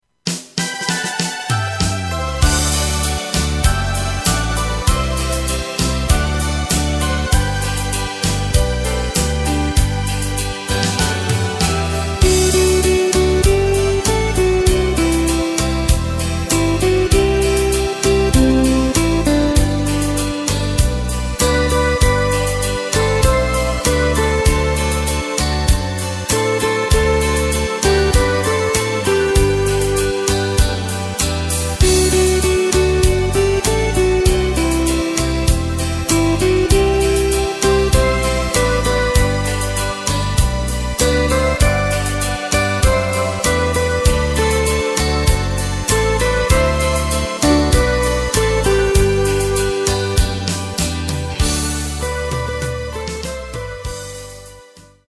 Takt:          4/4
Tempo:         98.00
Tonart:            G
Schlager aus dem Jahr 2002!